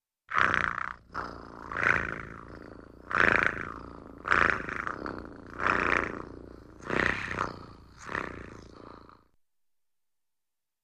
Cat Purring Steady.